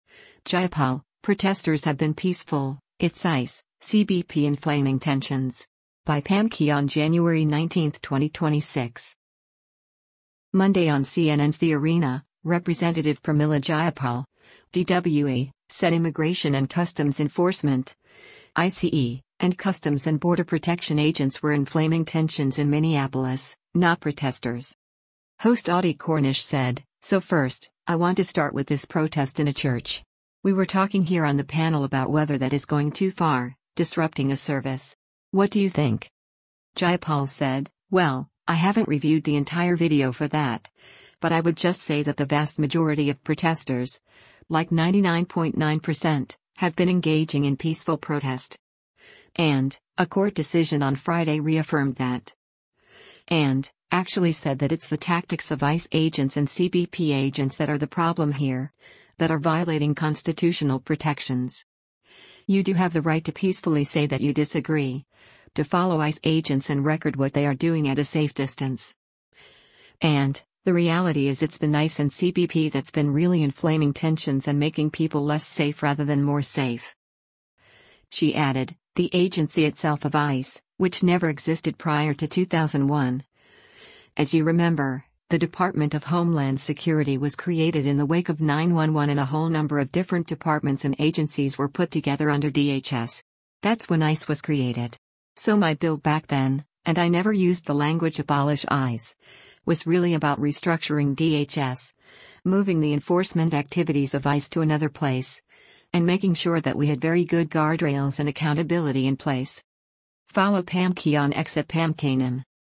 Monday on CNN’s “The Arena,” Rep. Pramila Jayapal (D-WA) said Immigration and Customs Enforcement (ICE) and Customs and Border Protection agents were “inflaming tensions” in Minneapolis, not protesters.